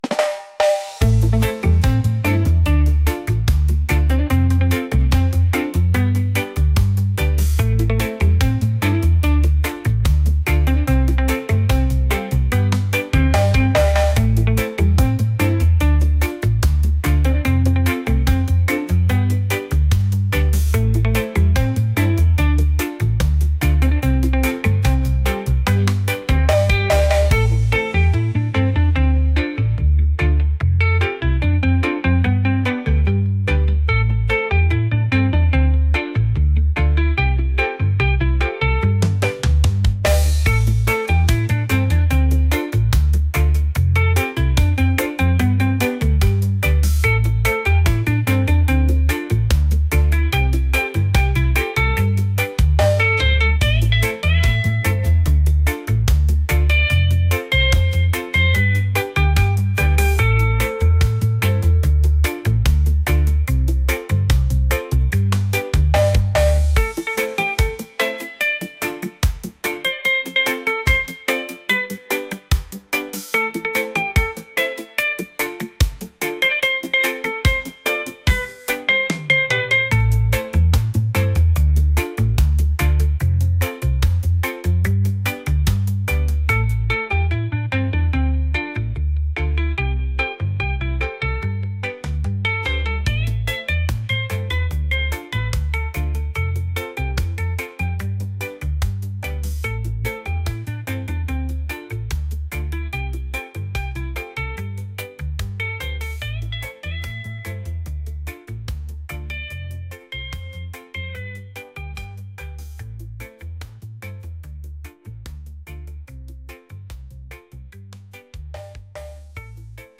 reggae | upbeat